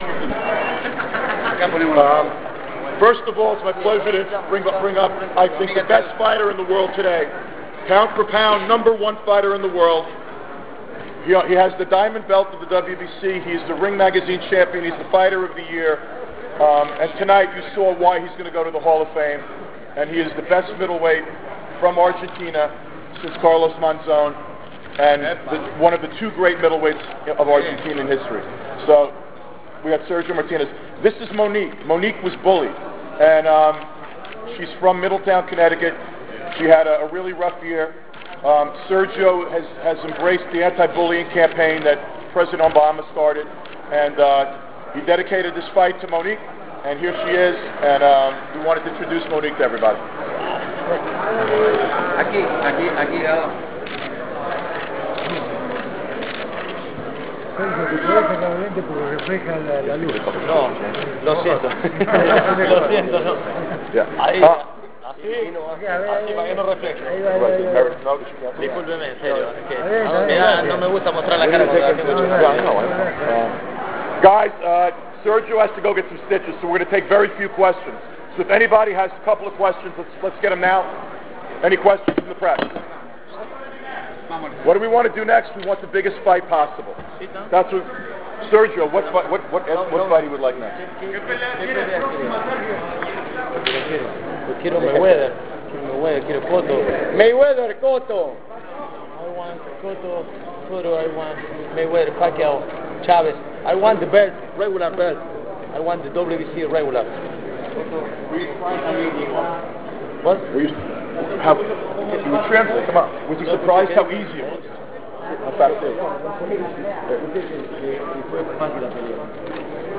2011 CLICK HERE TO LISTEN TO LIVE POST FIGHT PRESS CONFERENCE
Sergio-Martinez-Post-Press-Conference1.wav